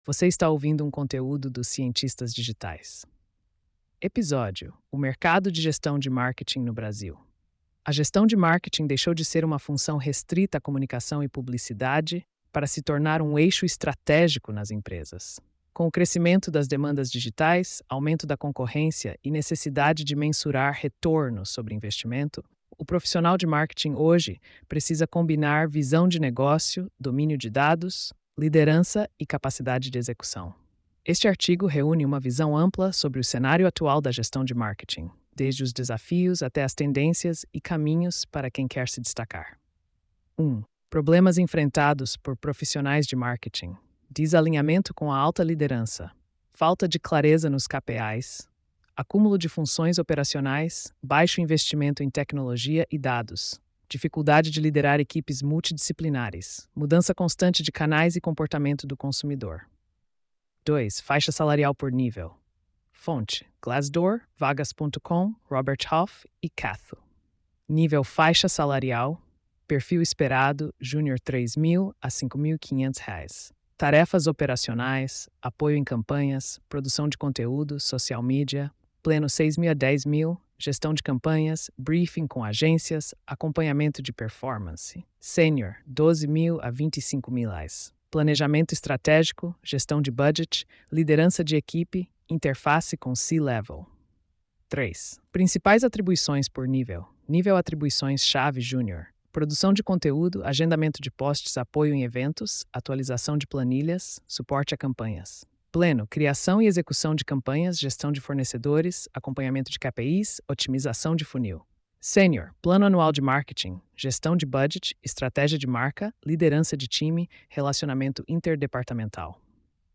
post-3349-tts.mp3